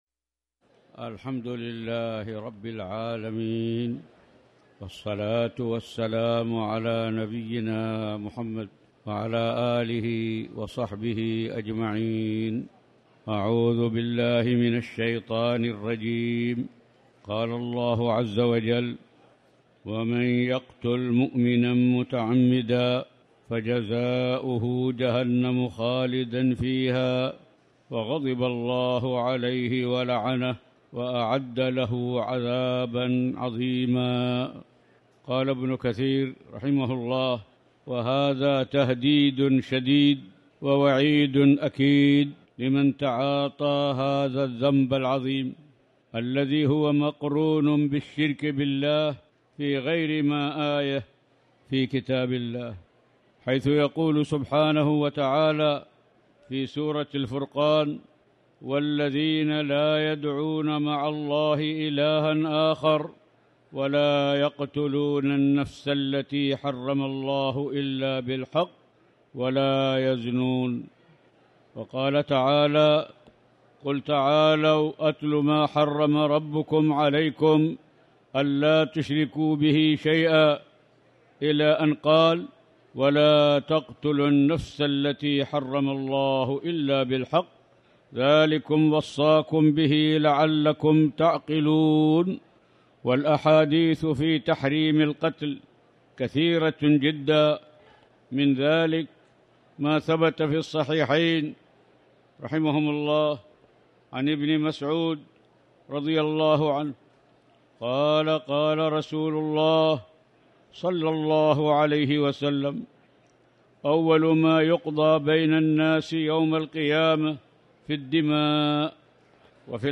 تاريخ النشر ١٩ ذو القعدة ١٤٣٨ هـ المكان: المسجد الحرام الشيخ